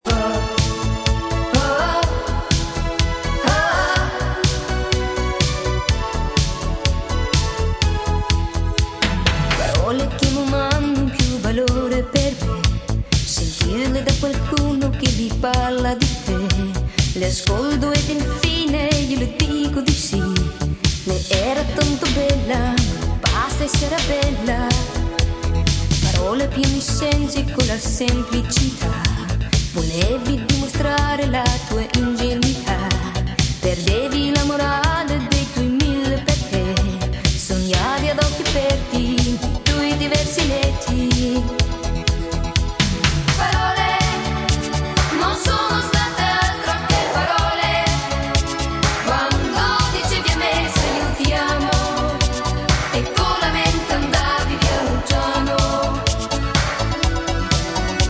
ポジティヴでロマンティックなメロディーにコケティッシュなイタリア語ヴォーカルが映えまくる逸品です。